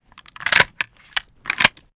machinegun_reload_02.wav